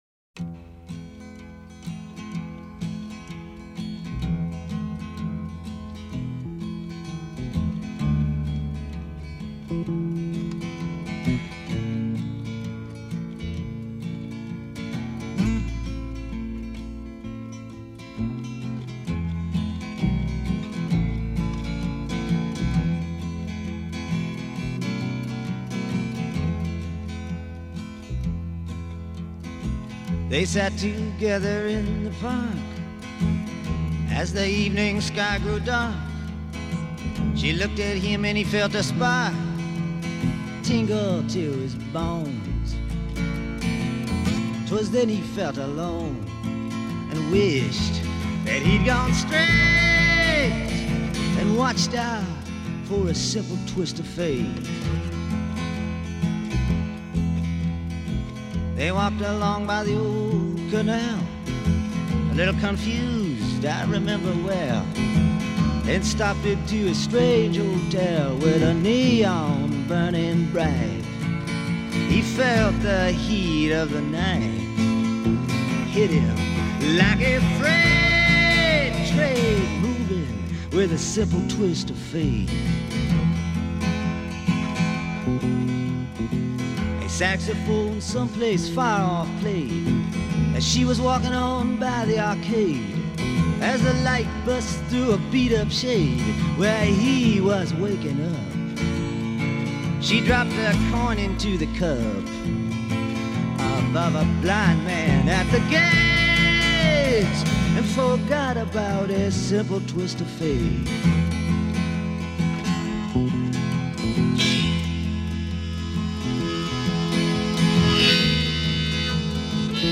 The confessional way he delivers that line always gets me.